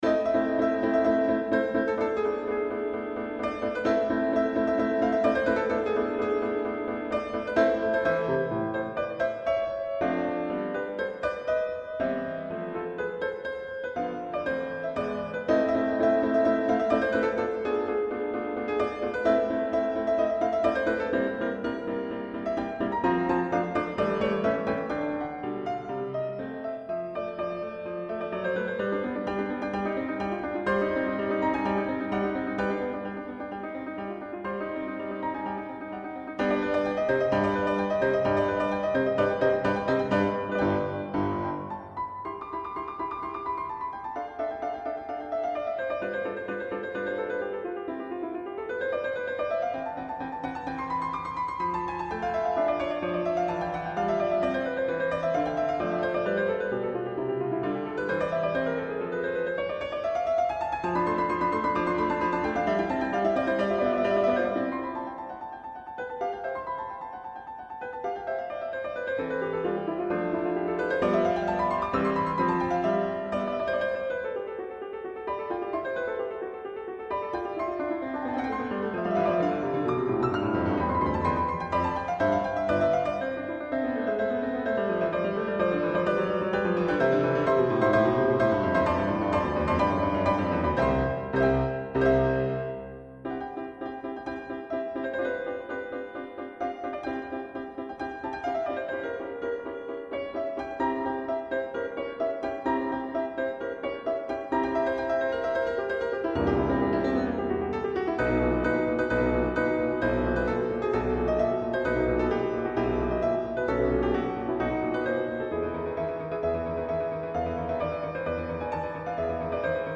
シュニットガー、プレトリウスはクセが強すぎる気もするが、面白い。
ラモーだと、居心地の悪い響きが少し増える。
平均律は優等生的でカッコイイが、それと引き換えに失うものもあった事が解る。